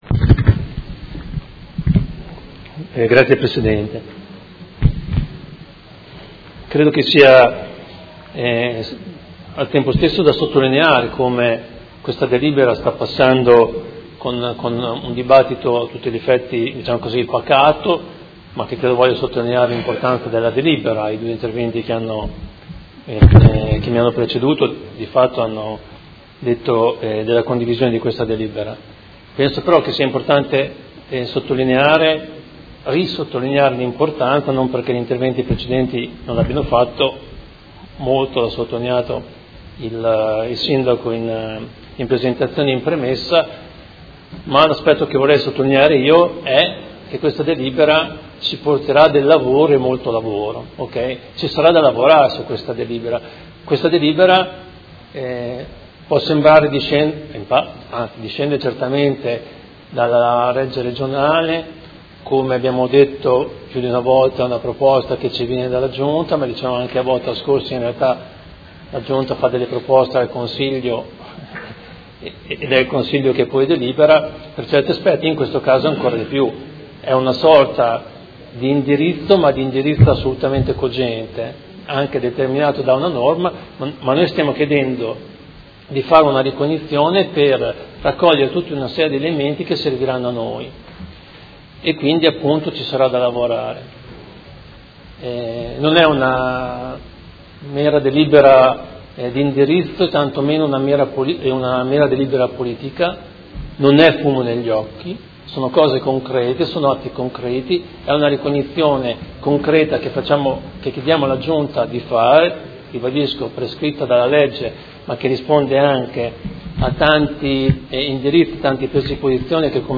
Fabio Poggi — Sito Audio Consiglio Comunale
Dibattito su proposta di deliberazione: Ricognizione e sospensione temporanea dell'efficacia e dell'esecuzione di provvedimenti, di accordi e di convenzioni non sottoscritte in materia urbanistica ed edilizia ai sensi dell'art. 21 quater legge 241/90, in relazione all'entrata in vigore della nuova “Disciplina regionale sulla tutela e l'uso del territorio”, L.R. n. 24/17 pubblicata sul BURER n. 340 in data 21 dicembre 2017